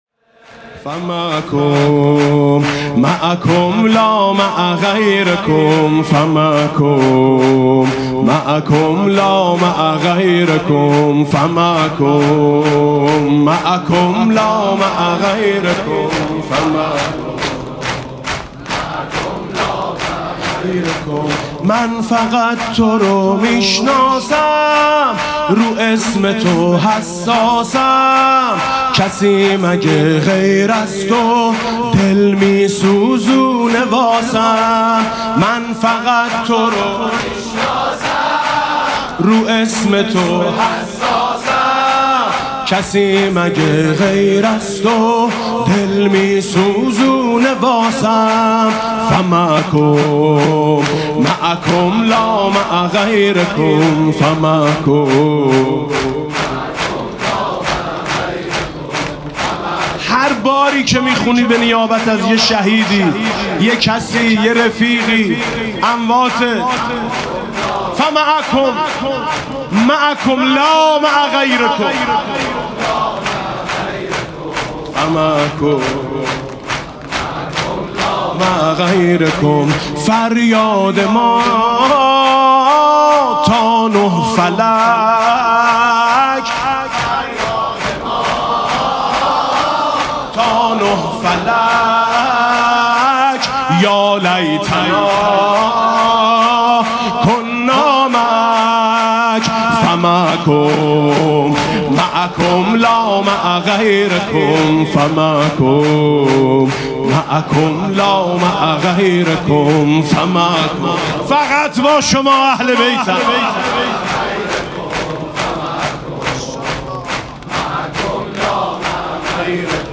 مسیر پیاده روی نجف تا کربلا [عمود ۹۰۹]
مناسبت: ایام پیاده روی اربعین حسینی
شور